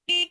Car Horn.wav